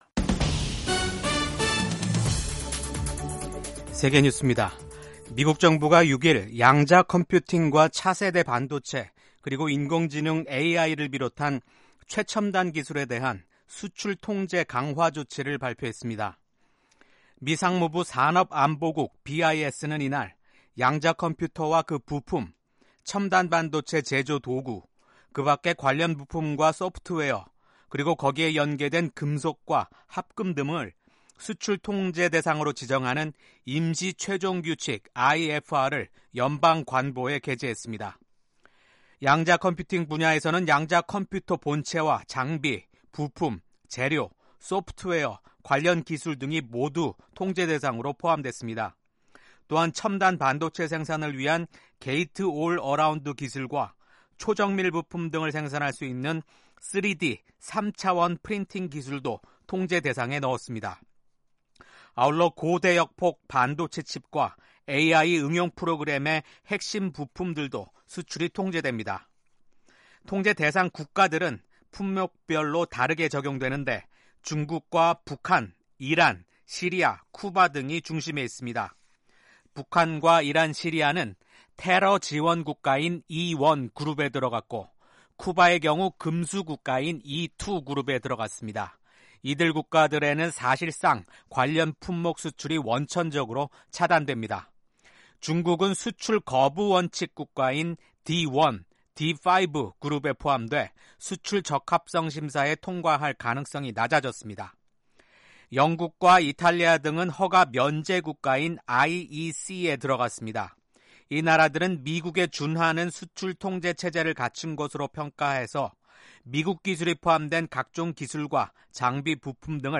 세계 뉴스와 함께 미국의 모든 것을 소개하는 '생방송 여기는 워싱턴입니다', 2024년 9월 7일 아침 방송입니다. 미국 조지아주에서 일어난 총격 사건 범인의 아버지가 살인 혐의로 기소됐습니다.